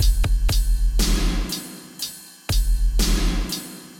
低音鼓9
描述：只是一脚
标签： bassdrum 打击乐器 样品 bassdrum 命中 单触发
声道立体声